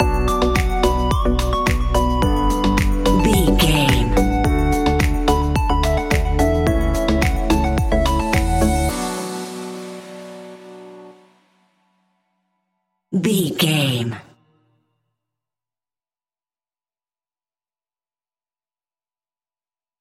Aeolian/Minor
uplifting
energetic
cheerful/happy
bouncy
synthesiser
drum machine
electro house
funky house
synth leads
synth bass